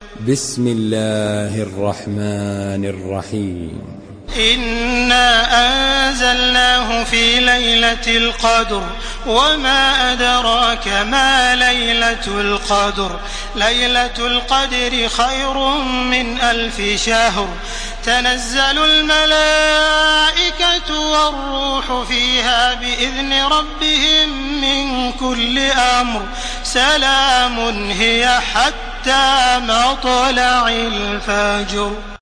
Surah Al-Qadr MP3 by Makkah Taraweeh 1431 in Hafs An Asim narration.
Murattal